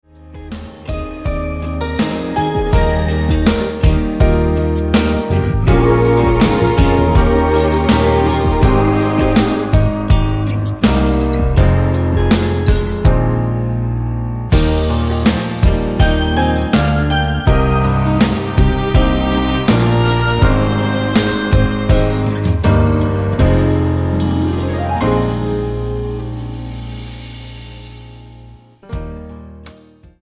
--> MP3 Demo abspielen...
Tonart:E-C#-F#-D# mit Chor